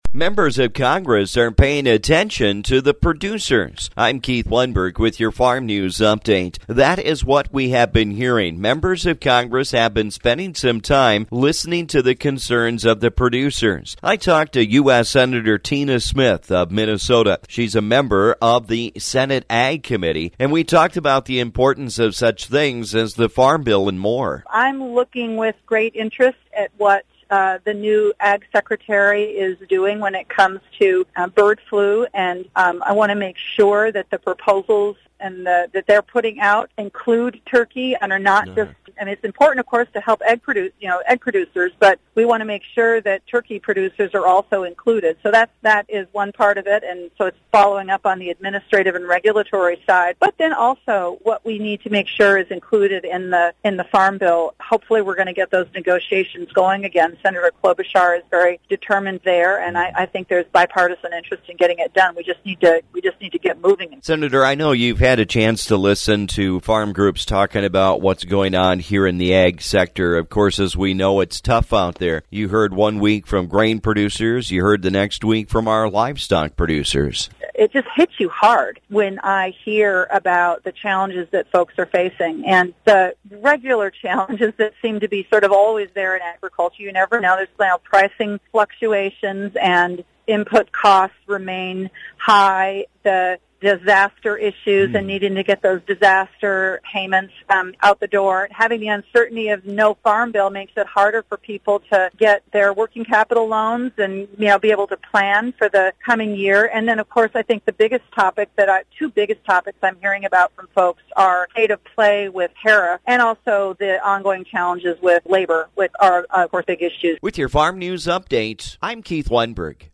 I’ve been spending time visiting with our Congressional Leaders about the importance of Farm Legislation. Today I talk with U.S. Senator Tina Smith of Minnesota.